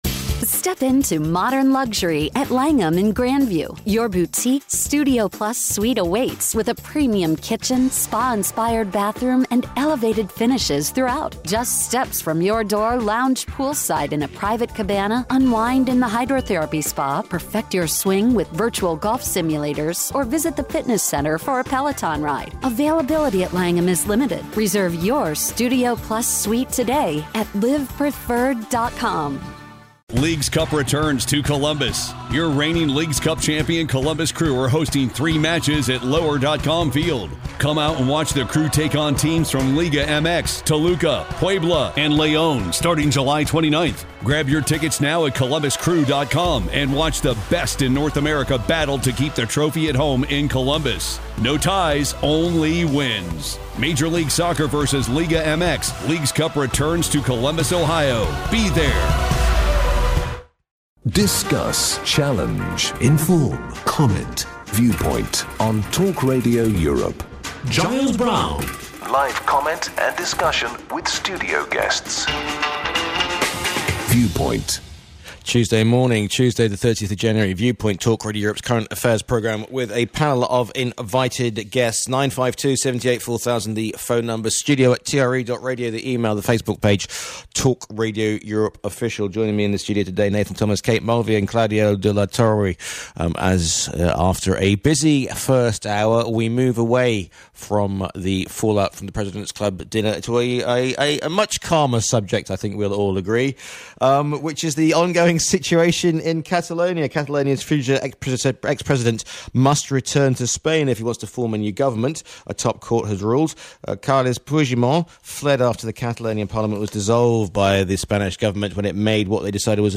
and a different panel of guests (politicians, business people, pundits) for each show. The panel discuss the major and breaking news stories of the week. The show is uncensored, often controversial, and full of heated debate.